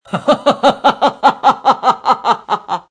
Descarga de Sonidos mp3 Gratis: risa 19.